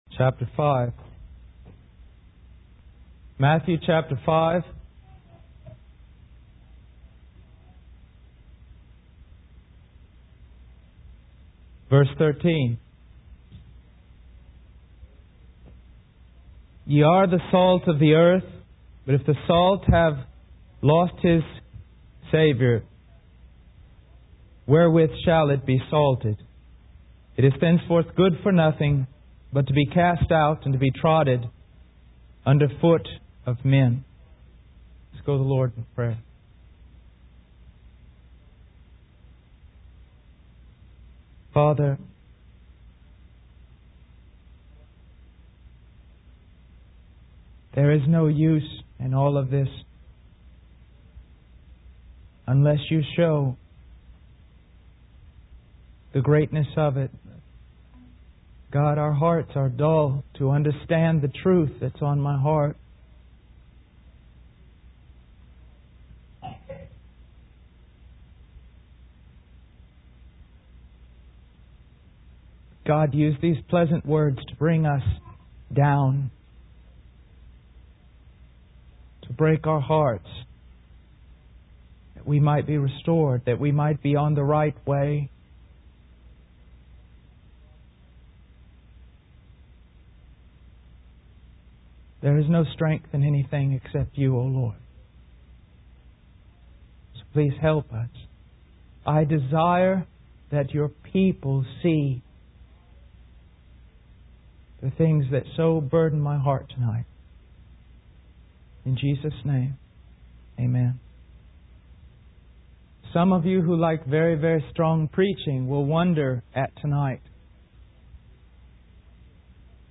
In this sermon, the preacher emphasizes that sin is an abomination to God, regardless of our own categorizations of sin. He urges preachers to focus on the wonderful message of Jesus Christ rather than relying on apologetics or evidence.